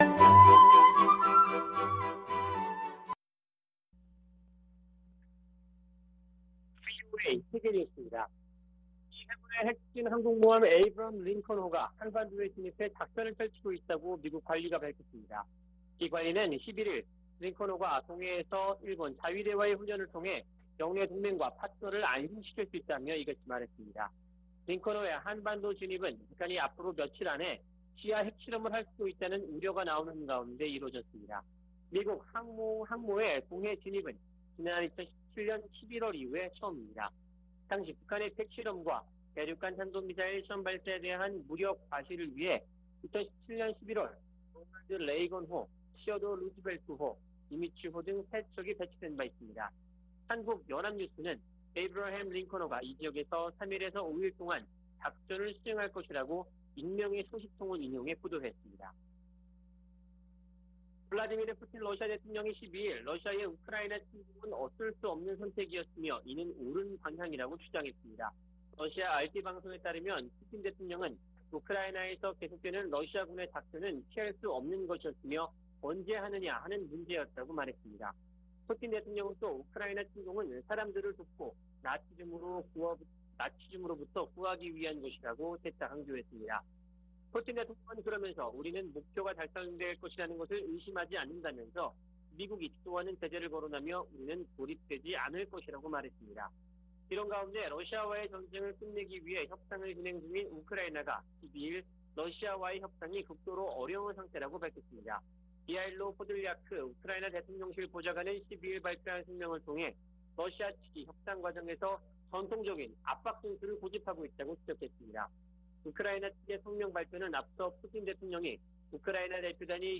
VOA 한국어 아침 뉴스 프로그램 '워싱턴 뉴스 광장' 2022년 4월 13일 방송입니다. 미국의 핵 추진 항공모함 에이브러햄 링컨함이 한반도 동해 공해상에 전개됐습니다. 한 때 한국 기를 달았던 유조선이 매각된 후 한국 항구를 떠나면서 ‘북한’을 다음 목적지로 신고했고 출항 허가까지 받은 것으로 확인됐습니다. 북한이 대륙간탄도미사일(ICBM)로 미국 본토를 타격할 수 있는 역량을 향상시켰다고 미국 의회조사국이 밝혔습니다.